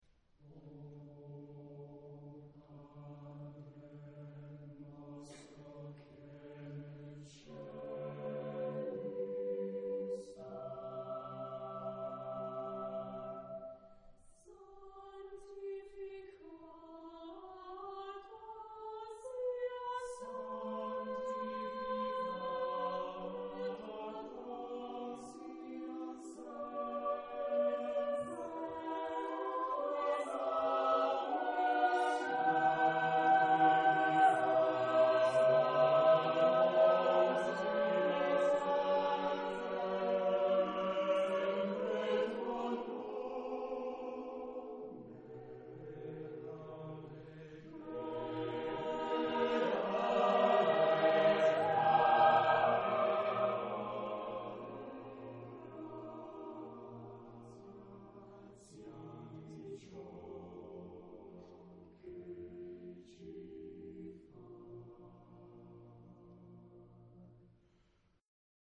Genre-Style-Forme : Sacré ; Prière
Caractère de la pièce : lent
Type de choeur : SMATB  (5 voix mixtes )
Tonalité : sol majeur
Consultable sous : Romantique Sacré Acappella